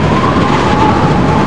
TORNADO.mp3